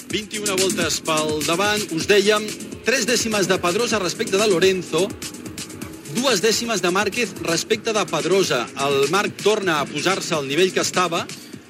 Transmissió de la cursa de Moto GP del Gran Premi de Catalunya de Motociclisme al Circuit de Catalunya
Esportiu